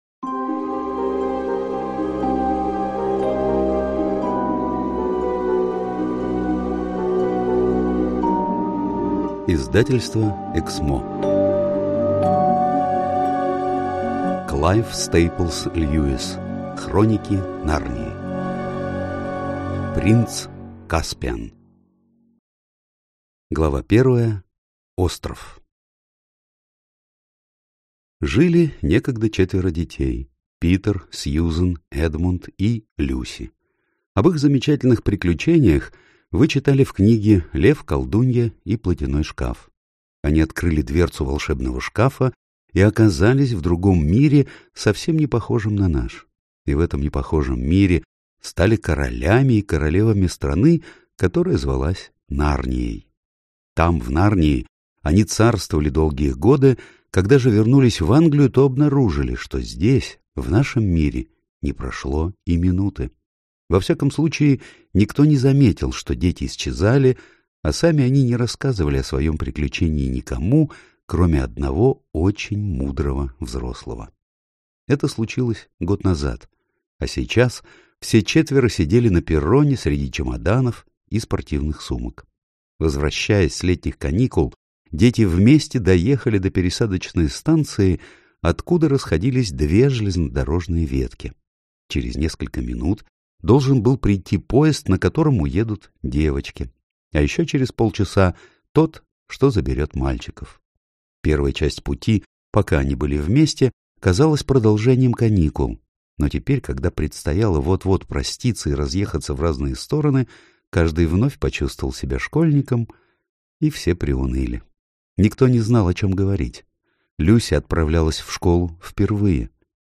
Аудиокнига Принц Каспиан | Библиотека аудиокниг